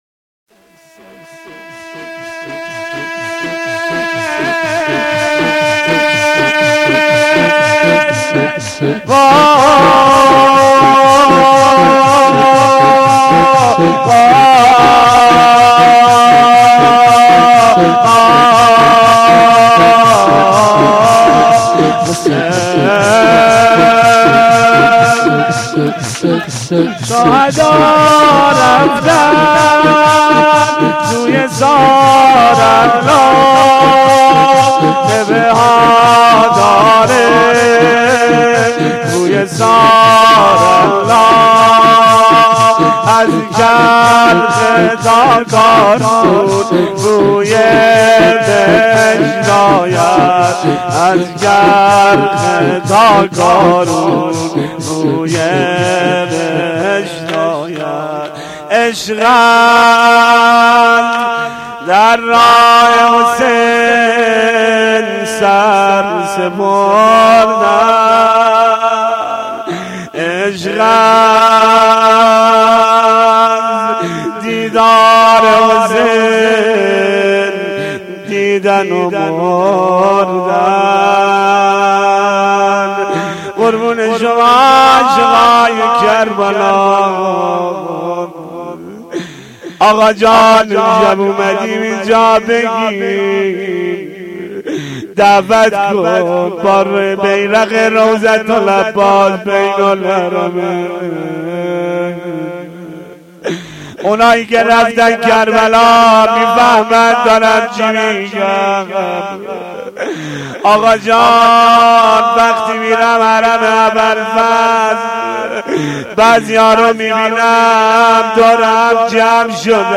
شور و روضه
shoor-roze-Rozatol-Abbas-Ramezan93-sh4.mp3